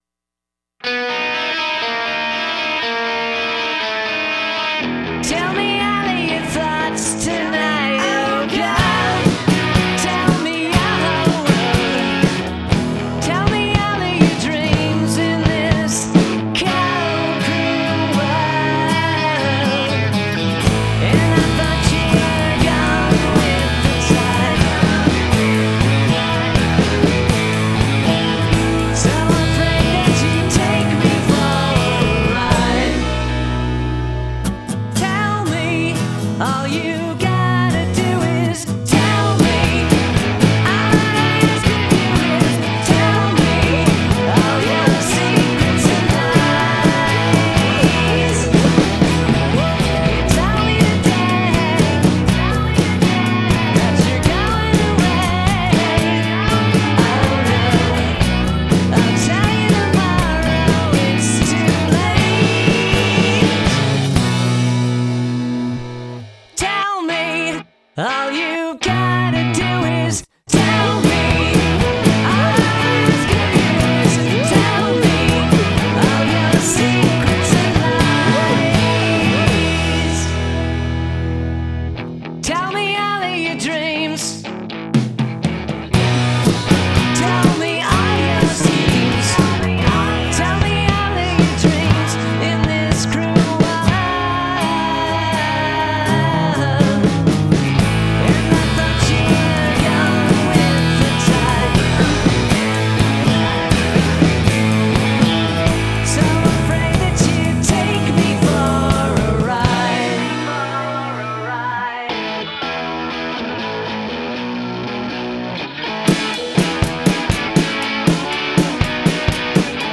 self-produced and home-recorded
classic mid-1960s British Invasion rock and roll sound
Love the wobbly synth that crops up all over the tune.